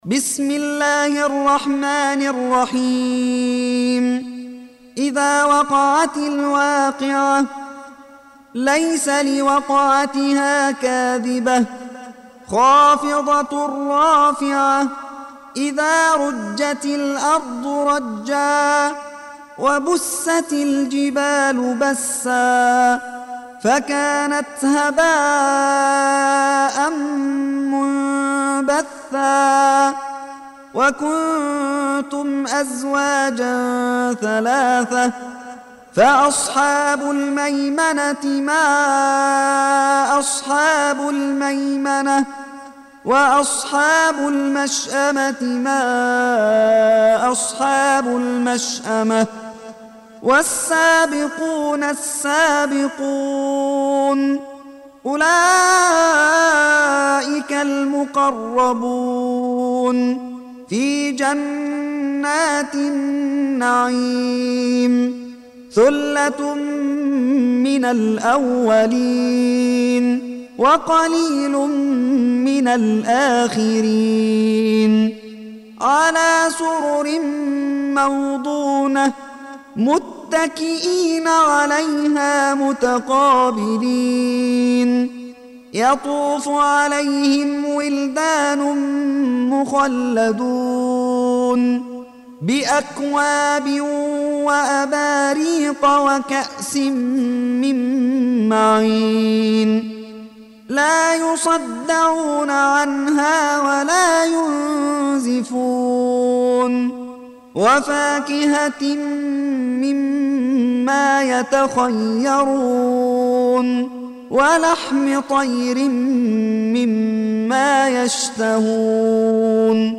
Surah Sequence تتابع السورة Download Surah حمّل السورة Reciting Murattalah Audio for 56. Surah Al-W�qi'ah سورة الواقعة N.B *Surah Includes Al-Basmalah Reciters Sequents تتابع التلاوات Reciters Repeats تكرار التلاوات